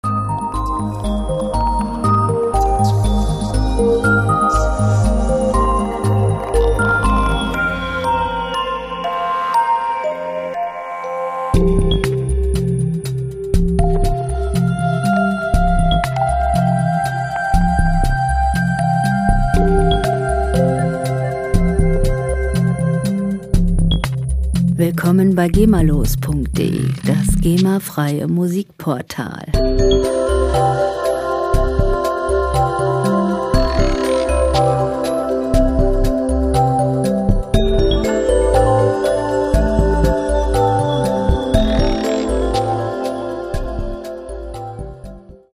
Filmmusik - Landschaften
Musikstil: New Age
Tempo: 120 bpm
Tonart: C-Dur
Charakter: leicht, still
Instrumentierung: Bells, Glocken, Bass, Synthesizer, E-Piano